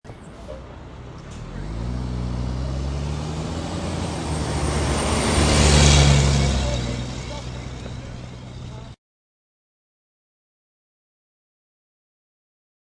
Download Monster Truck sound effect for free.
Monster Truck